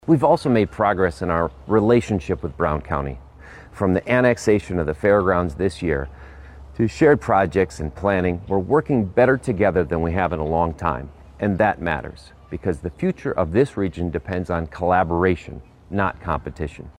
The address was delivered at Storybook Land.